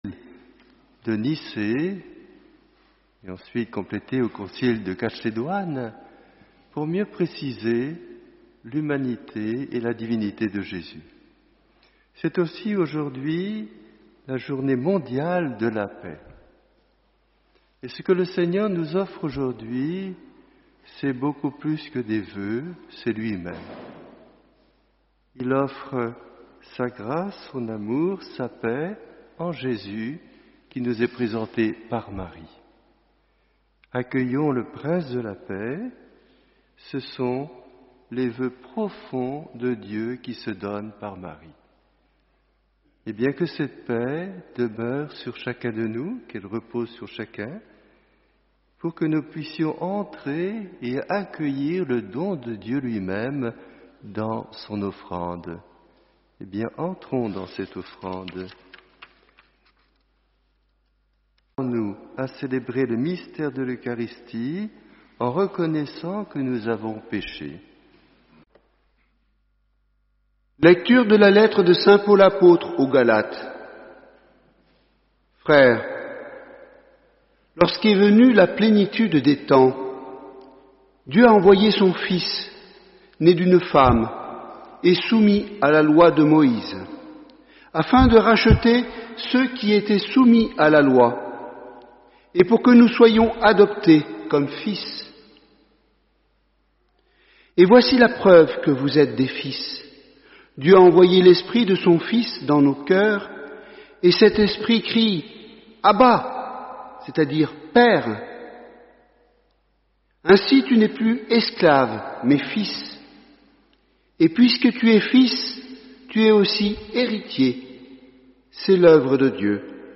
Homélie : Chers frères et sœurs, à Noël, nous avons eu la joie de célébrer la naissance de Jésus dans une vraie famille, Jésus, enfant, au milieu de Marie et de Joseph.